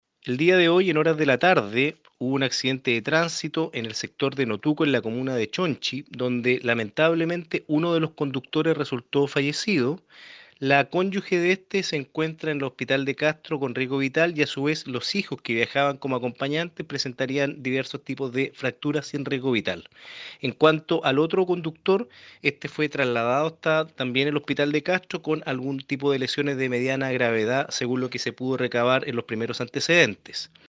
Allí, la colisión de dos vehículos menores en el sector Notuco, dejó una persona fallecida y 6 personas lesionadas, dijo el Fiscal adjunto de Quinchao y Castro, Cristian Mena.